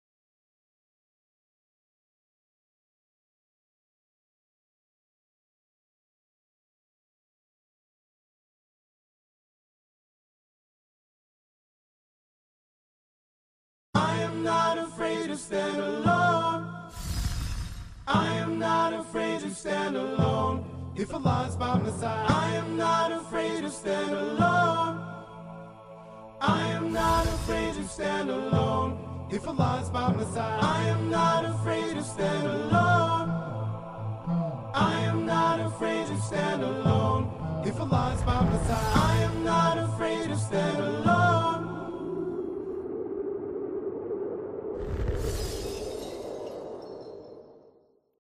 No Instruments only vocal.